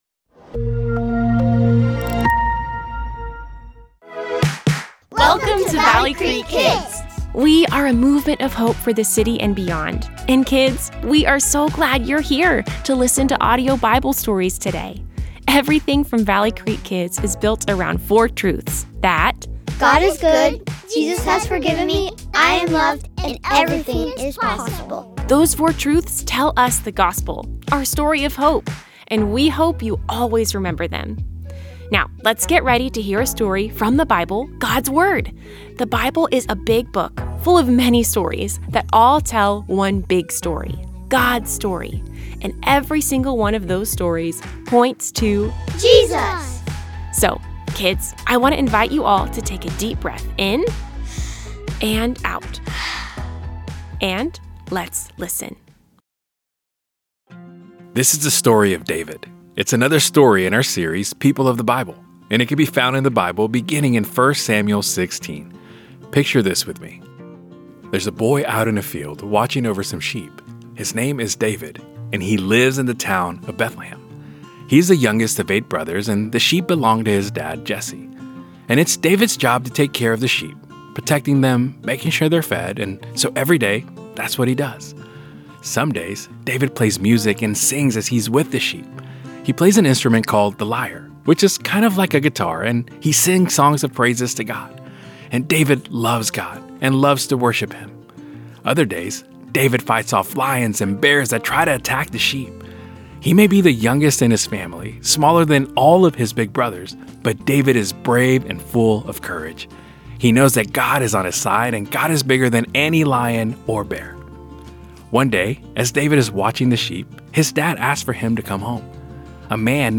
Kids Audio Bible Stories